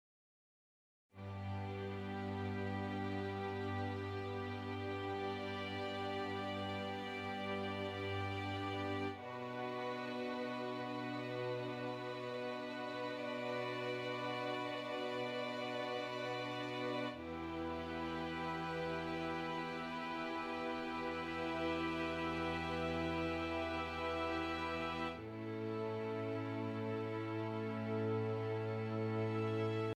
Relaxing Violin and Heavenly Cello Music